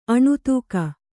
♪ aṇu tūka